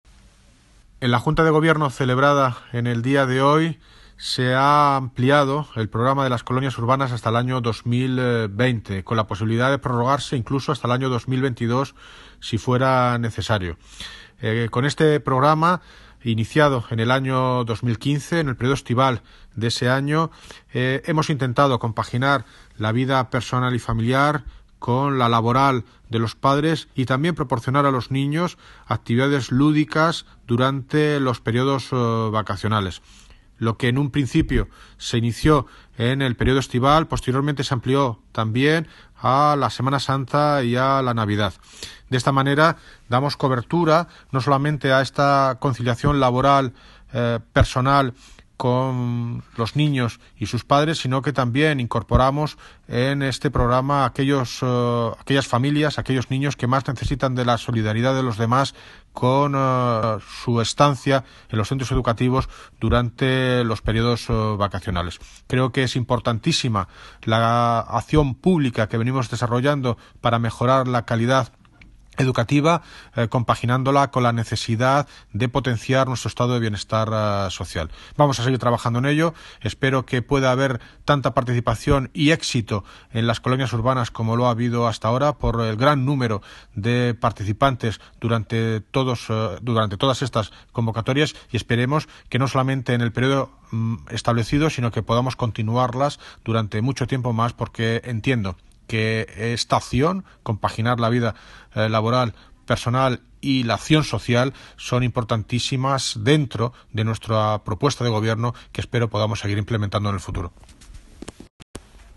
Audio - David Lucas (Alcalde de Móstoles) Sobre Colonias Urbanas